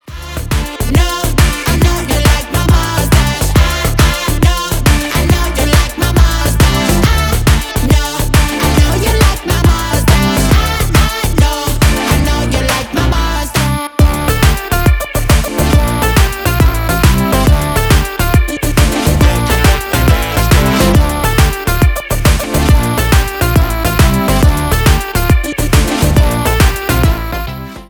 Жанр: Поп
# бас, # веселые, # громкие